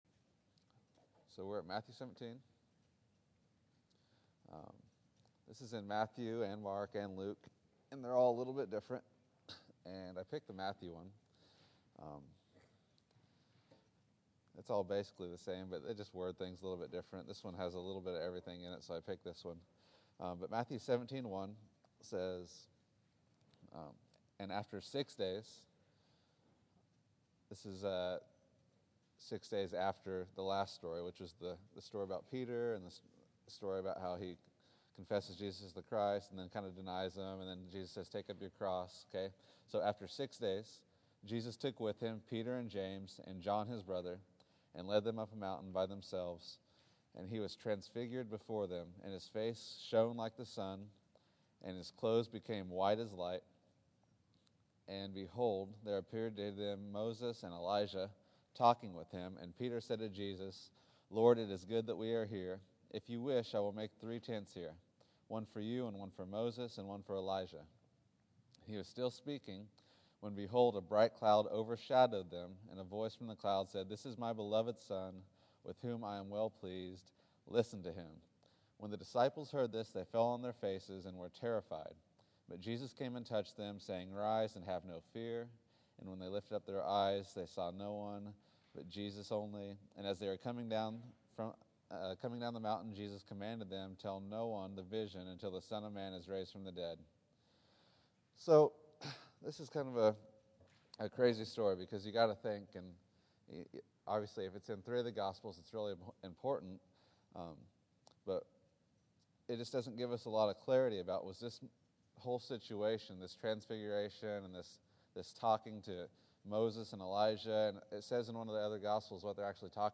Matthew 17:1-8 December 29, 2013 Category: Sunday School | Location: El Dorado Back to the Resource Library Three truths seen in the transfiguration.